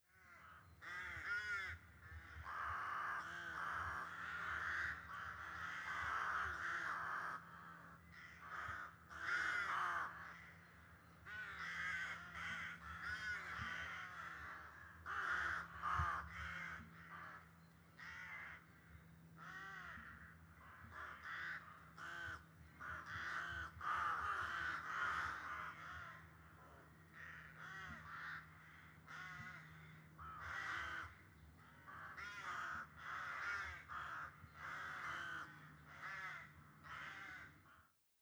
Crows-cawing.wav